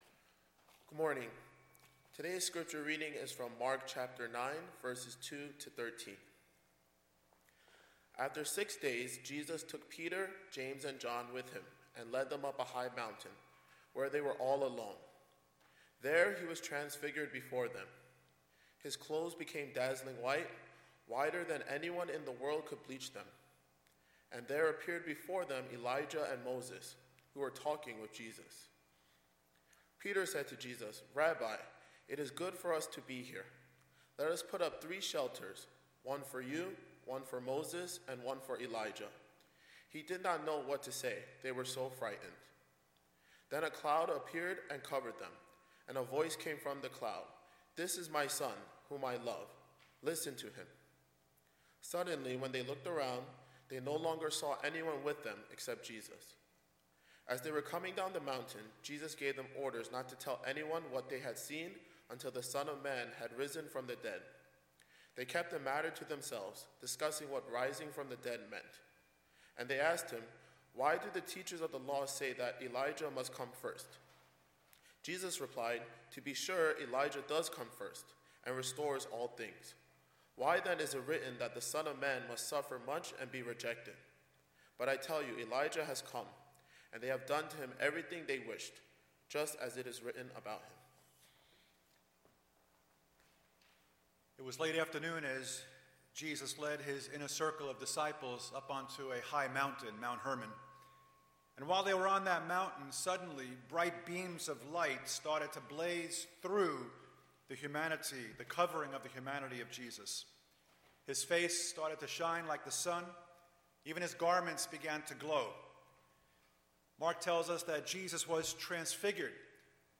English Sermons | First Baptist Church of Flushing